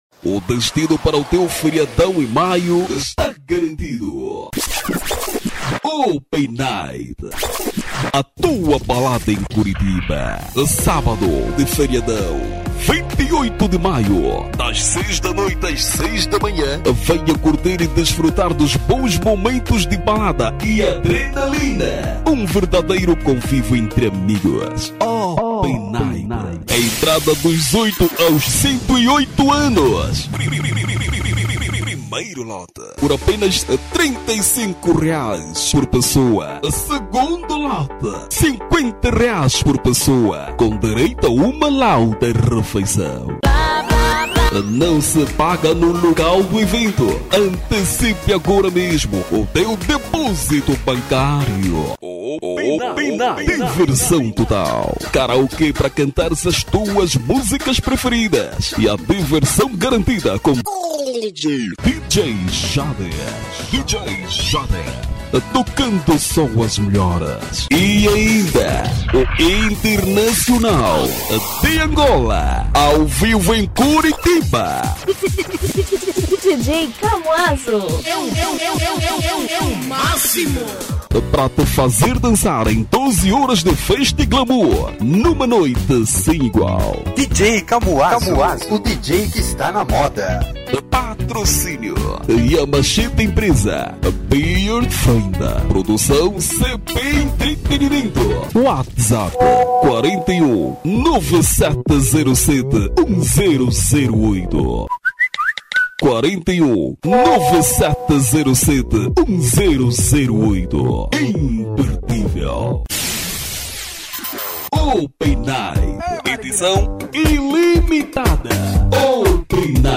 Masculino
Evento - Open Night